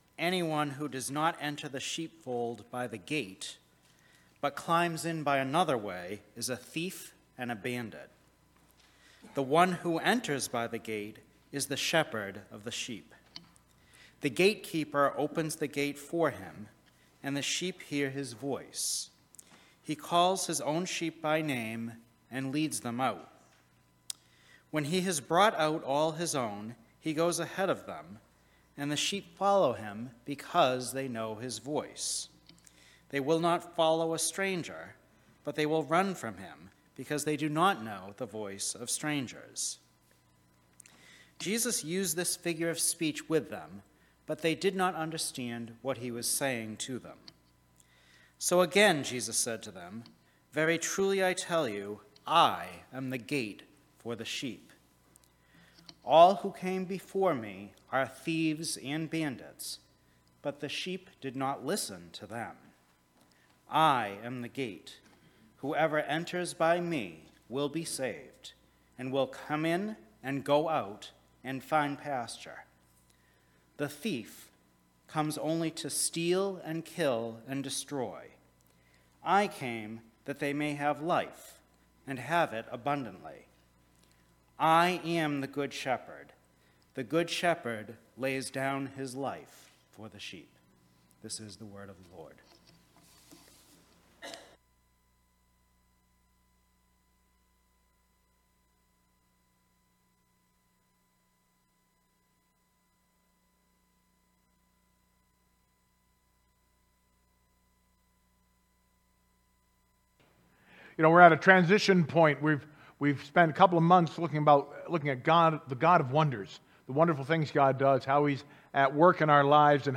Scripture-Reading-and-Sermon-June-4-2023.mp3